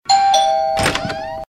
DOORBELL AND DOOR OPENING.mp3
A small apartment doorbell ringing, as the owner slowly opens a squeaking door.
.WAV .MP3 .OGG 0:00 / 0:01 Type Mp3 Duration 0:01 Size 256,61 KB Samplerate 44100 Hz Bitrate 256 kbps Channels Stereo A small apartment doorbell ringing, as the owner slowly opens a squeaking door.
doorbell_and_door_opening_fb4.ogg